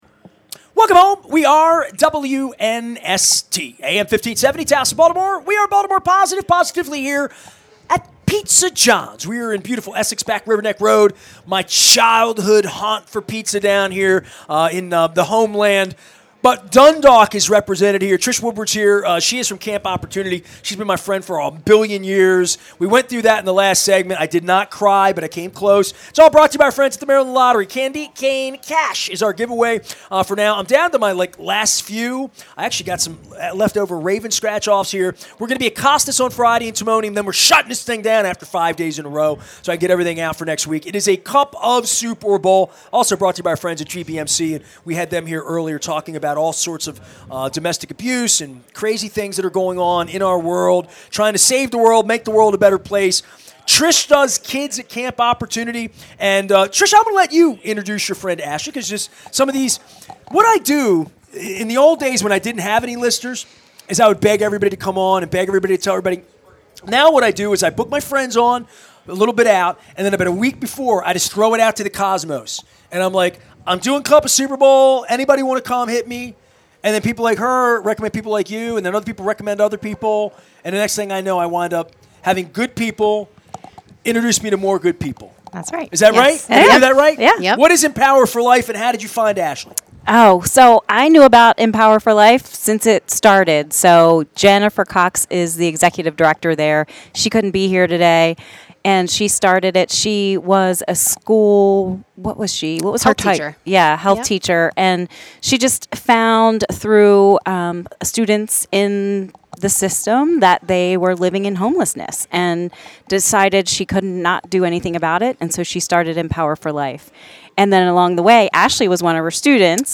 at Pizza John's in Essex